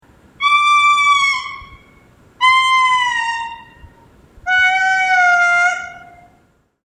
What do lemurs sound like?
"...most of the time the song is
monophonic with occasional
The sound is a little like that recording of whale song that everyone has."
oneLemur.mp3